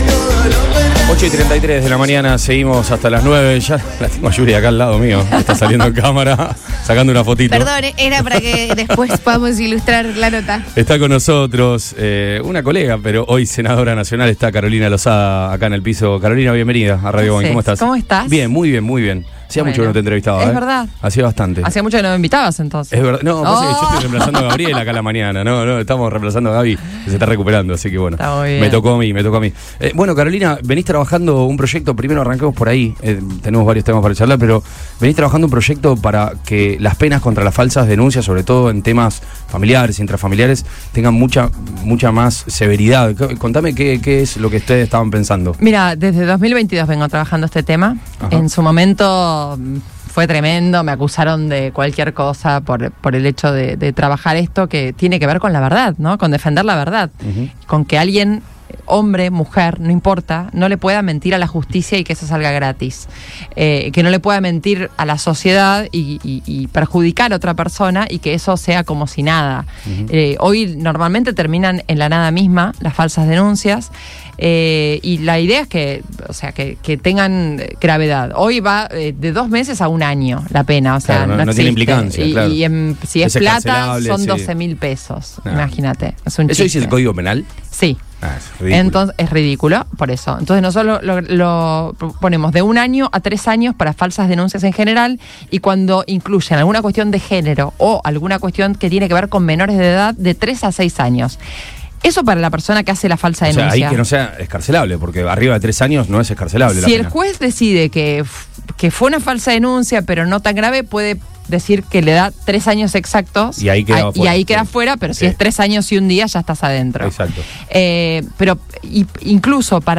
La senadora nacional por Santa Fe, Carolina Losada, visitó los estudios de Radio Boing y dialogó con Radio Boing, trabaja desde 2022 en un proyecto de ley que busca endurecer las penas por falsas denuncias, especialmente en el ámbito familiar o de género.